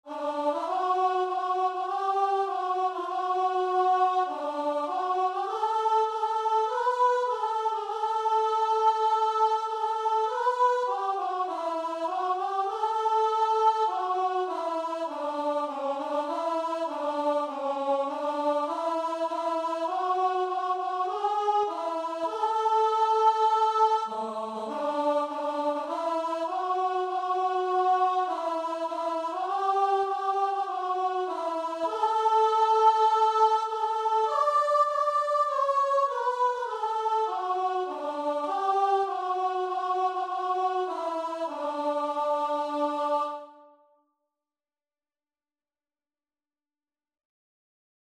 Christian Christian Guitar and Vocal Sheet Music
4/4 (View more 4/4 Music)
Classical (View more Classical Guitar and Vocal Music)